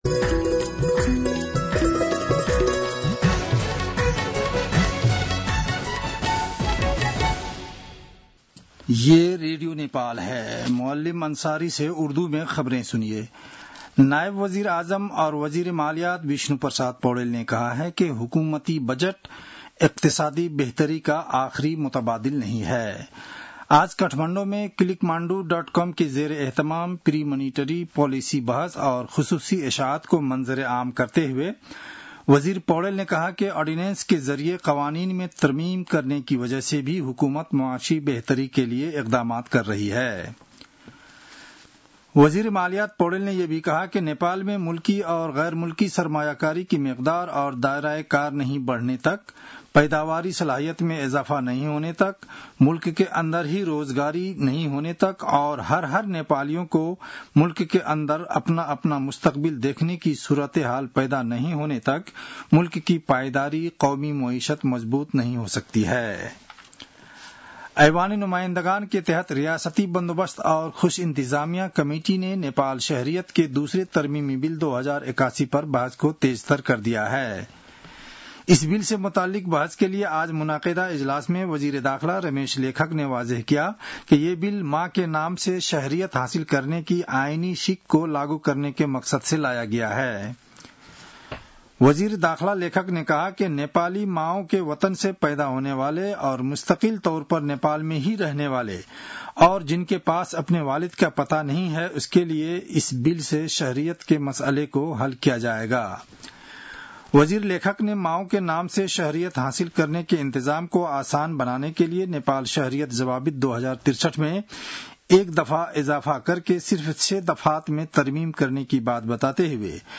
उर्दु भाषामा समाचार : २७ जेठ , २०८२